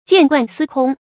見慣司空 注音： ㄐㄧㄢˋ ㄍㄨㄢˋ ㄙㄧ ㄎㄨㄙ 讀音讀法： 意思解釋： 謂經常看到，不足為奇。